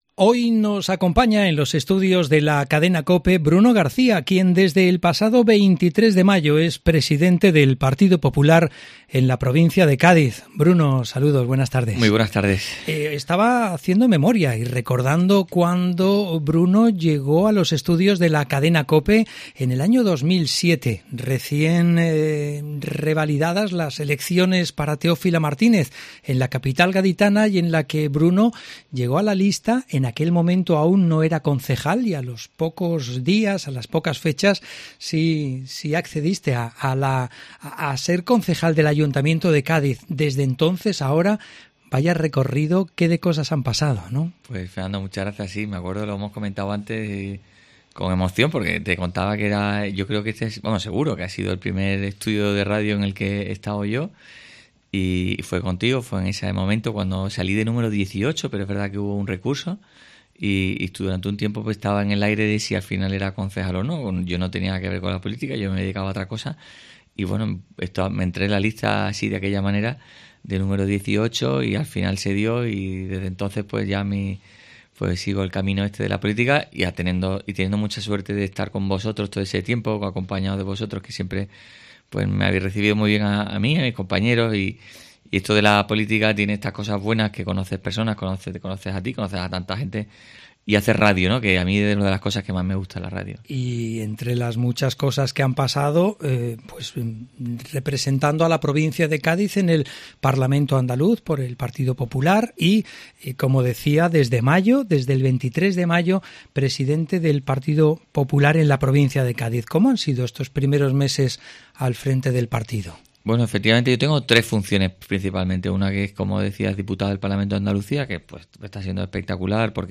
Bruno García, habla en los estudios de la Cadena Cope, de turismo, educación, industria y sanidad, como algunos de los pilares clave para la...
Entrevista Bruno García, Pte. PP prov. Cádiz 20/sept/2021